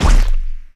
poly_shoot_bio.wav